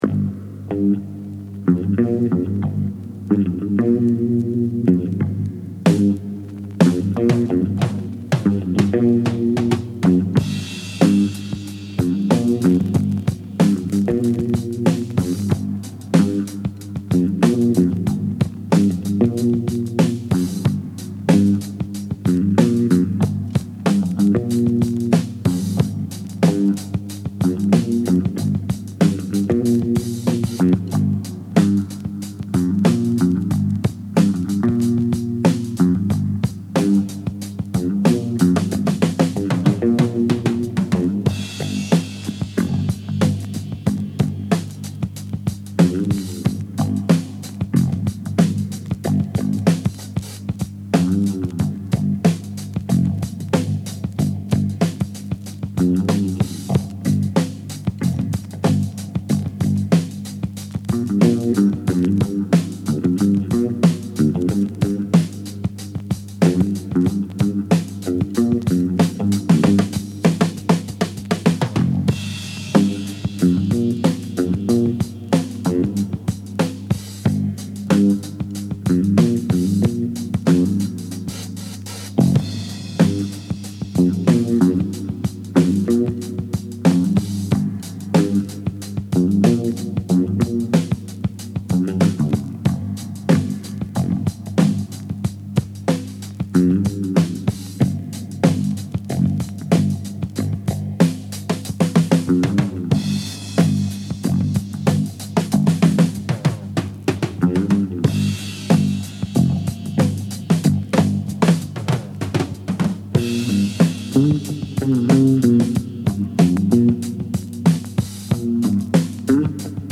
It’s still, after all these years, a killer groove.